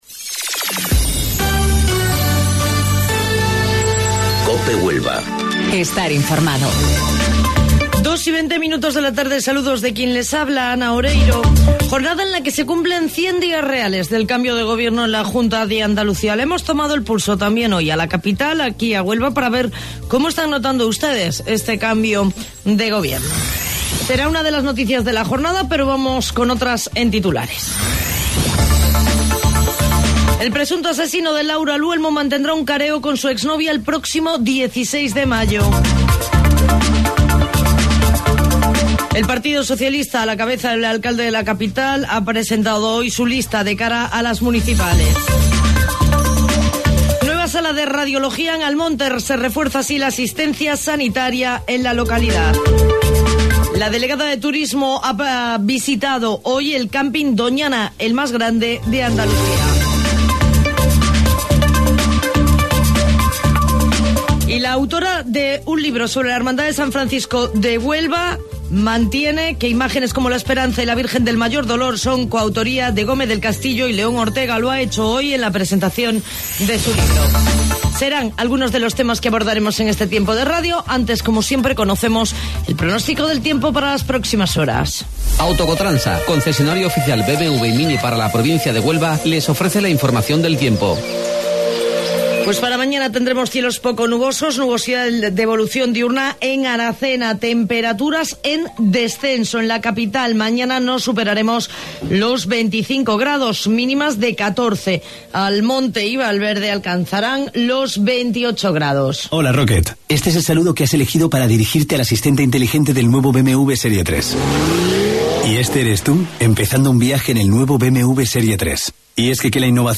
AUDIO: Informativo Local 14:20 del 2 de Mayo